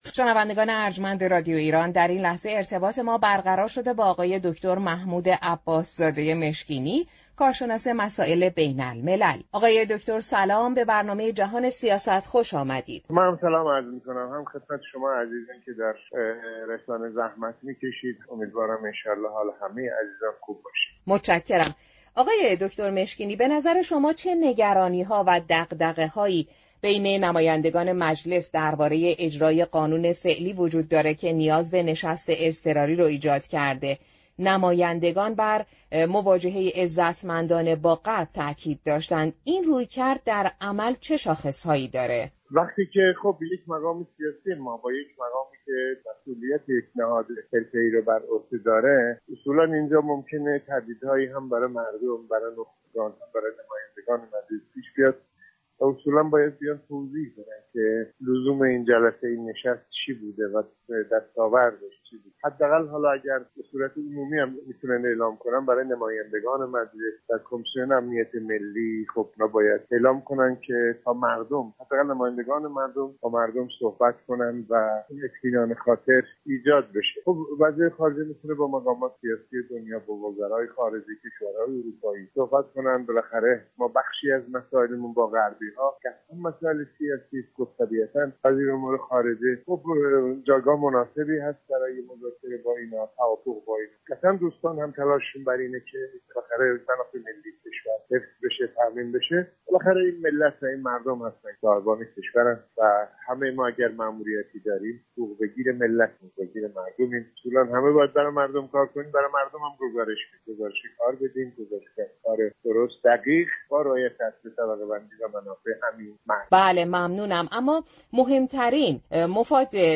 محمود عباس‌زاده‌مشكینی كارشناس مسائل بین‌الملل گفت: دولت در مراودات خود با كشورهای اروپایی حتما منافع ملی را در نظر می‌گیرد.